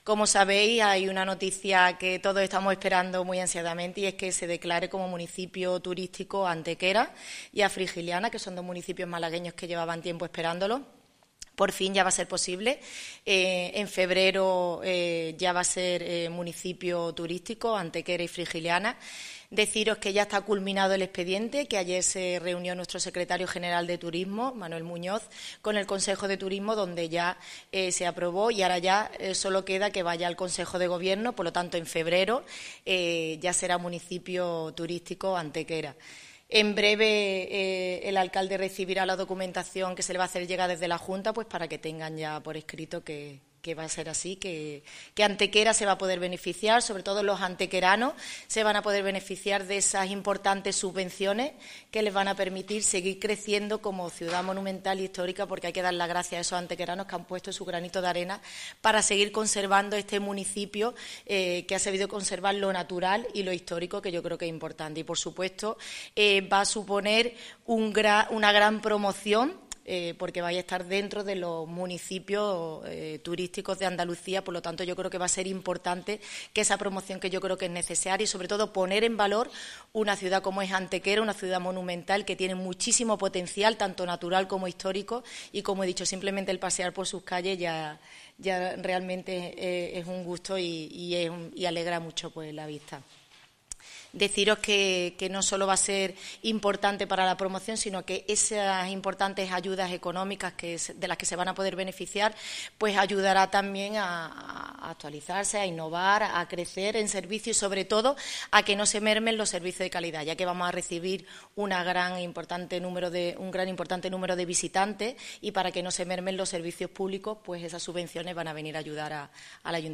Así lo han anunciado en una rueda de prensa celebrada en el Salón de los Reyes y en la que la Delegada explicaba que ya ha quedado culminado todo el expediente al respecto, quedando solo su aprobación definitiva en el mes de febrero a través del Consejo de Gobierno de la Junta de Andalucía.
Cortes de voz